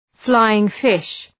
Leave a reply flying fish Dëgjoni shqiptimin https